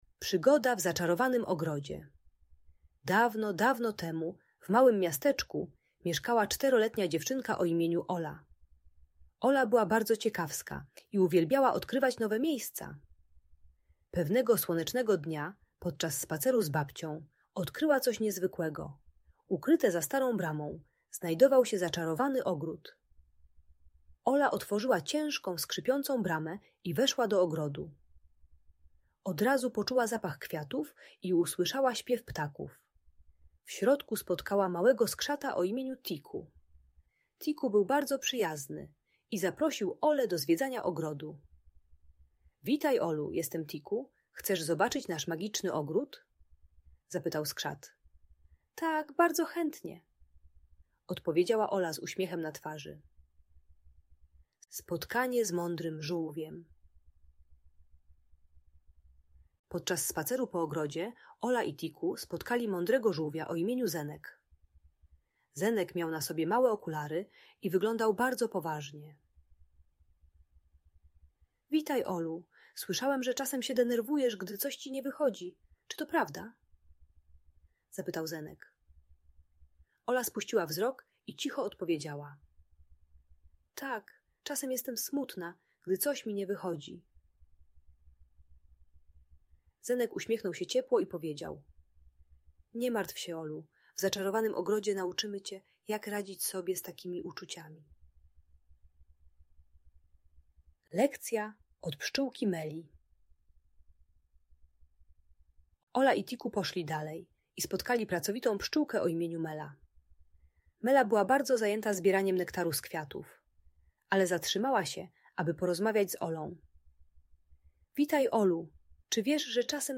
Przygoda w Zaczarowanym Ogrodzie - Magiczna Story - Audiobajka